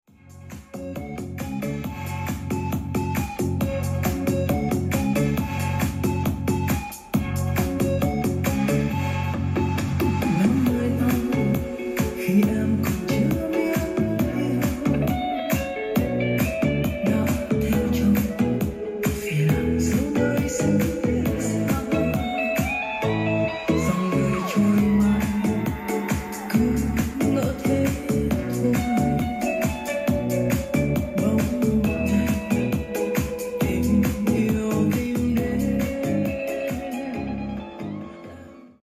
âm nghe cực nghiện, bass sâu tiếng ấm